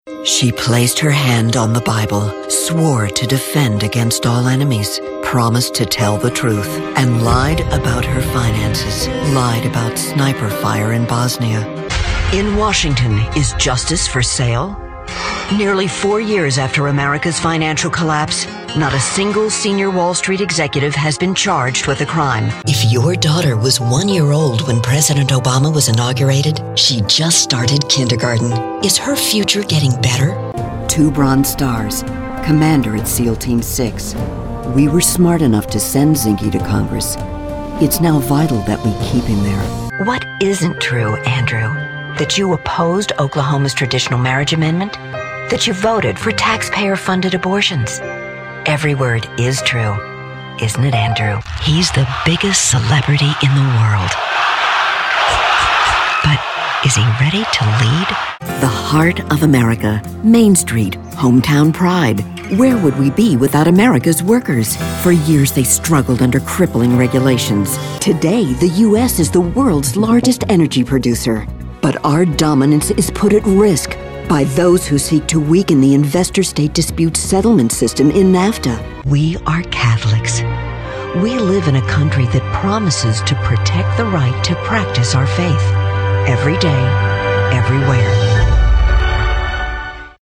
AUDIO AND VIDEO VOICEOVER DEMOS
Political Commercial